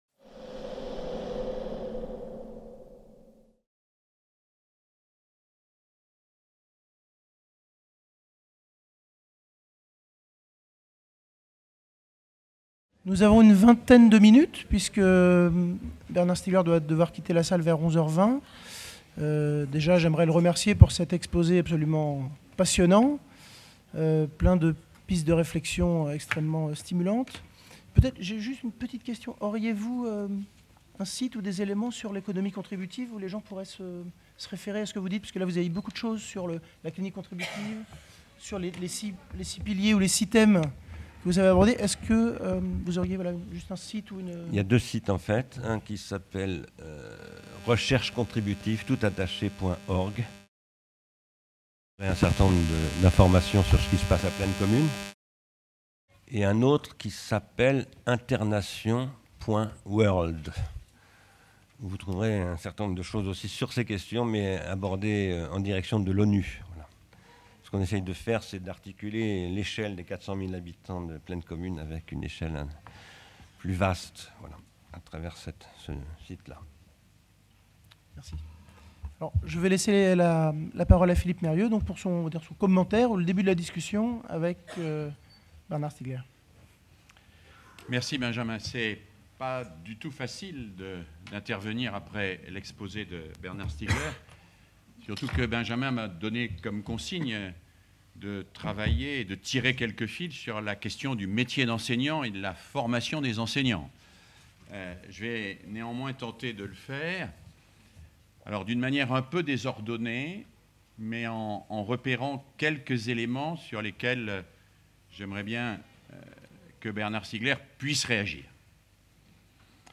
Quels rôles pour l’école et la formation de ses enseignants dans les évolutions à venir du savoir et du travail ? C’est à partir de cette question que l’ESPE de Caen et le laboratoire du CIRNEF ont le plaisir d’accueillir une conférence de Bernard Stiegler suivie d’une discussion avec Philippe Meirieu.